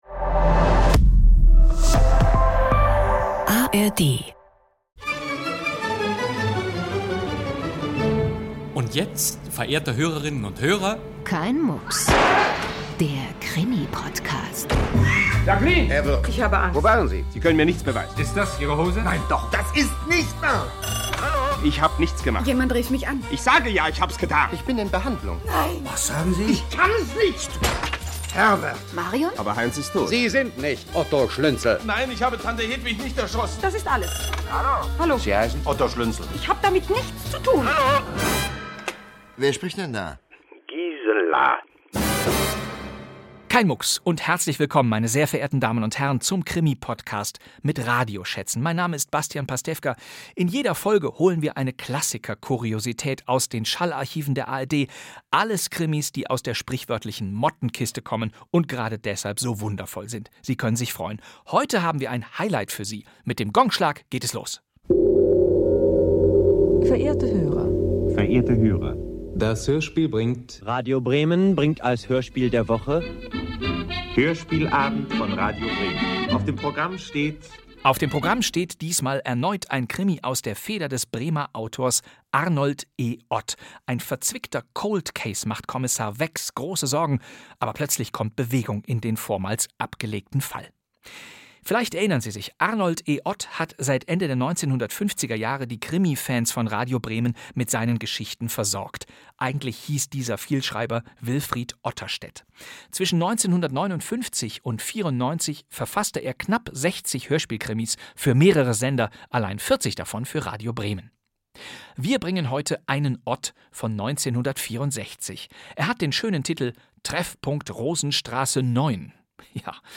1 Inspektor Hornleigh: Der Tod greift ein. Krimi-Podcast mit Bastian Pastewka 1:00:16